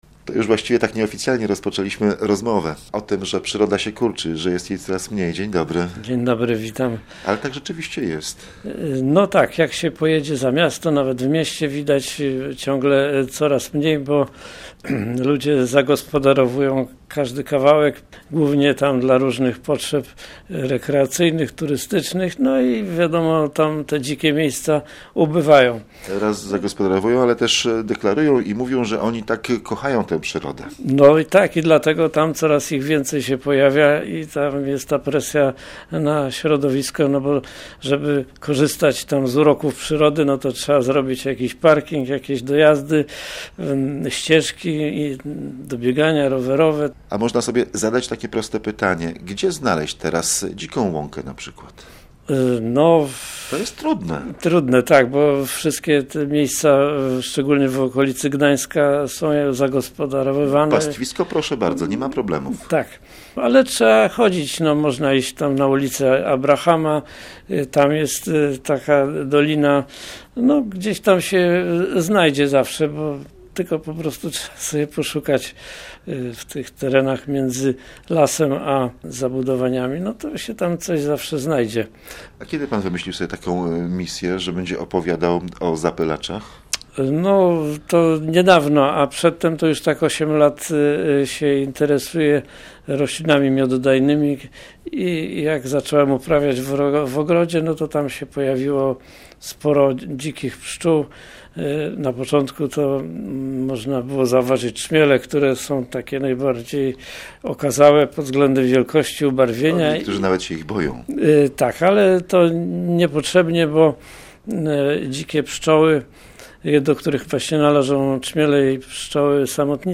Rozmowa w Magazynie Ekologicznym to zaproszenie na "Spotkania czwartkowe".